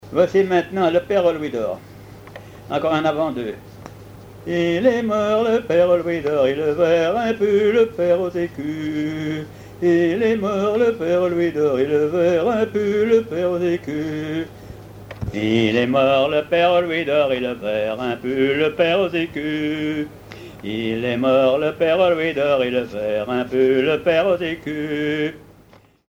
branle : avant-deux
chansons à ripouner ou à répondre
Pièce musicale inédite